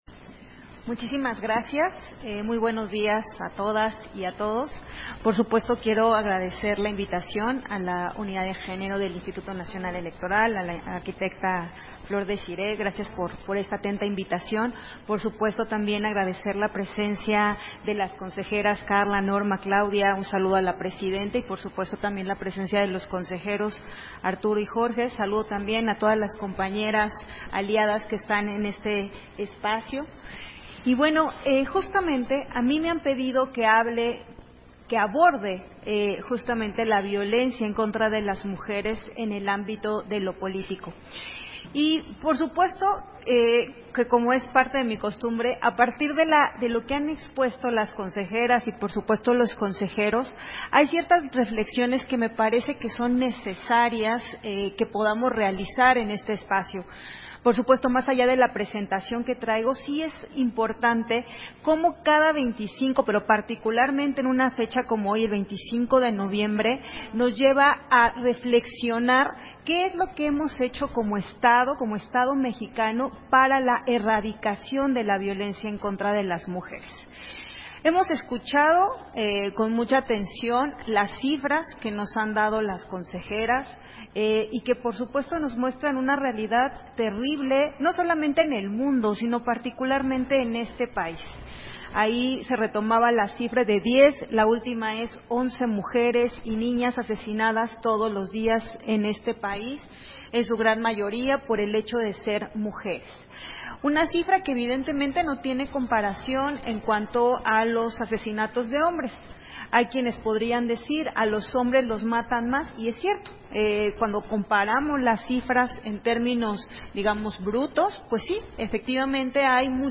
251124_AUDIO_CONFERENCIA-MAGISTRAL-ACCESO-A-LA-JUSTICIA-DE-LAS-MUJERES-EN-POLITICA-1 - Central Electoral